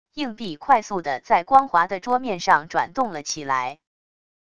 硬币快速的在光滑的桌面上转动了起来wav音频